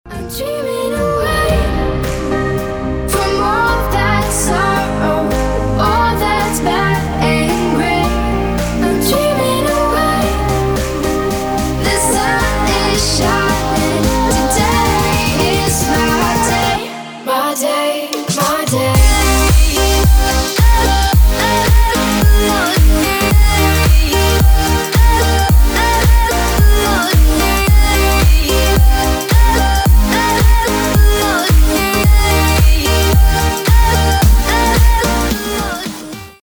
• Качество: 320, Stereo
красивый женский голос
house